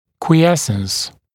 [kwɪ’esns][куи’эснс]покой, состояние покоя